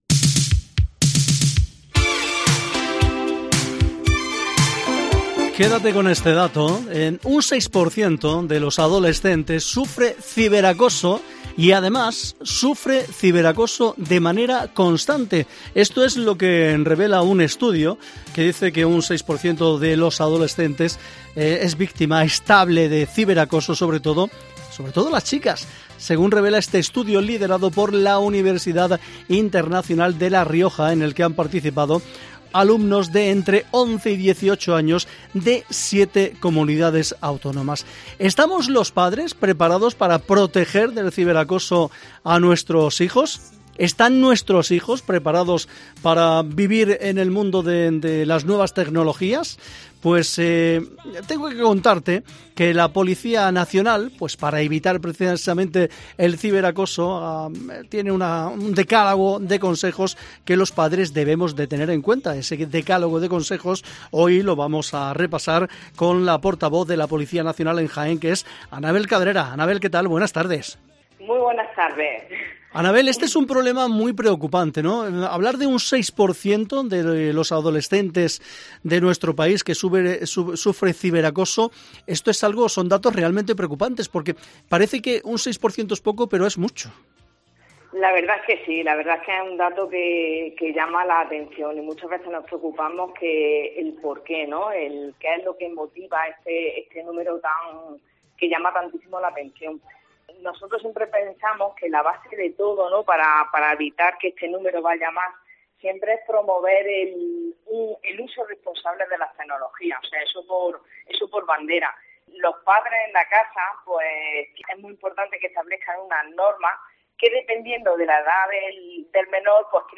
Ciberacoso: "La regla de oro es que no hagas a nadie lo que no te gustaría que te hiciesen a tí". En Mediodía COPE charlamos con